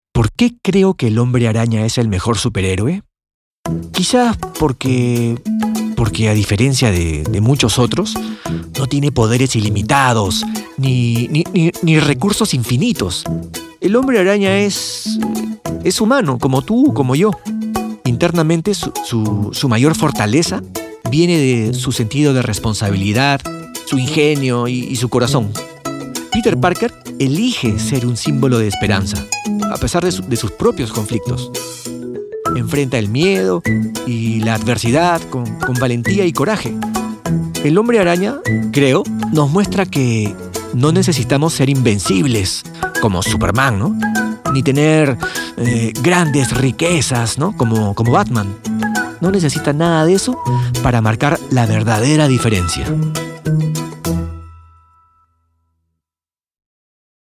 Erzählung
Ich habe mein eigenes Studio.